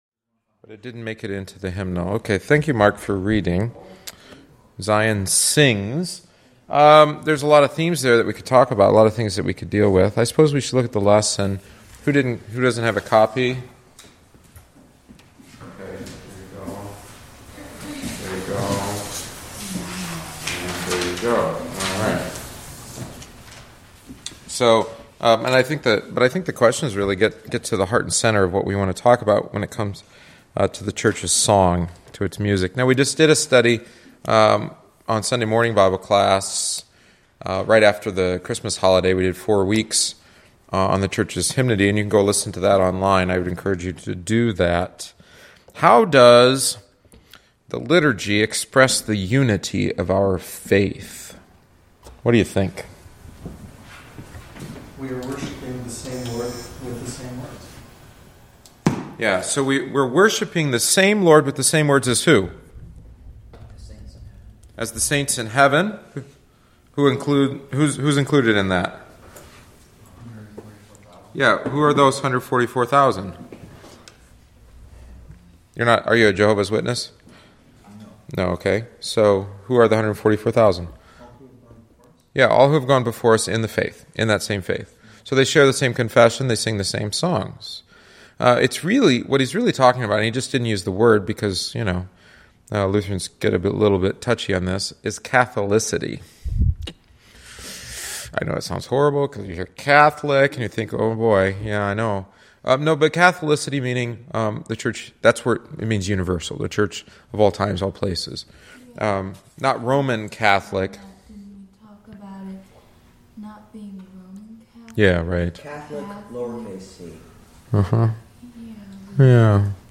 The attached audio omits the text of the book and includes only our discussion.